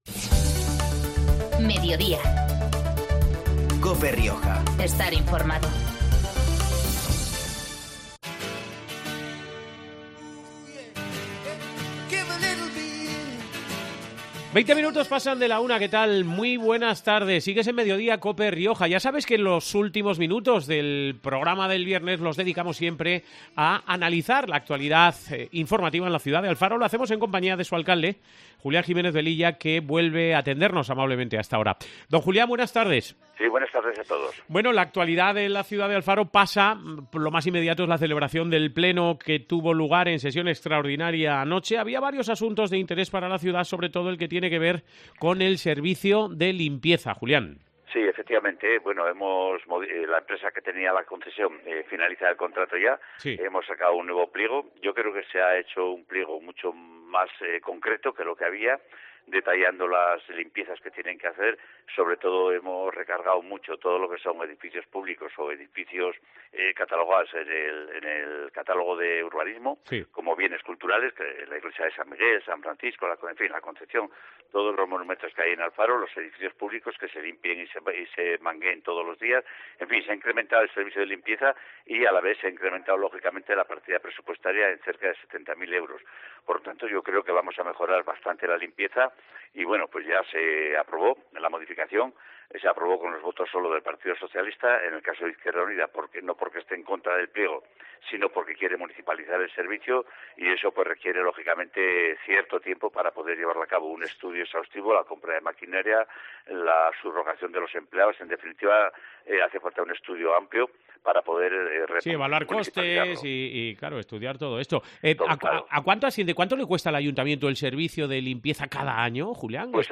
El alcalde alfareño es Julián Jiménez Velilla y este mediodía ha explicado en COPE algunos detalles del futuro nuevo contrato.